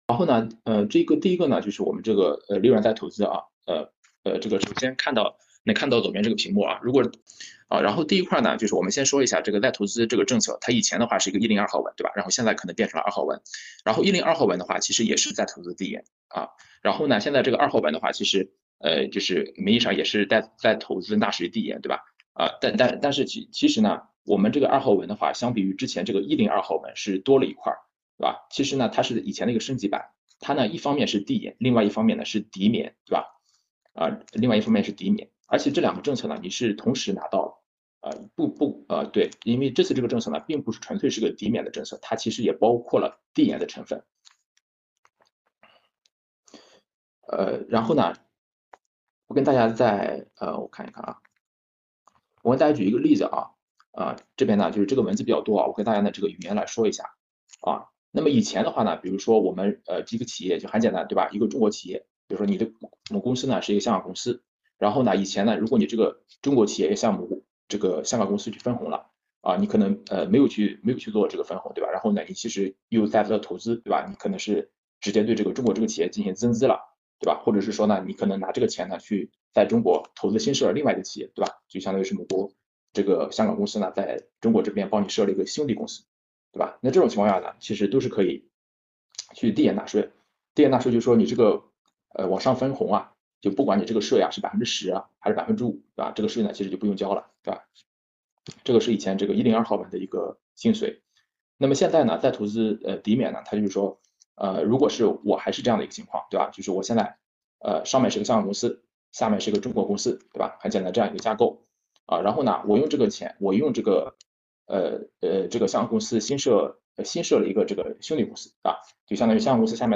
视频会议
8月15日【视频会议】境内利润再投资税收抵免新政解读及相关热点探讨.mp3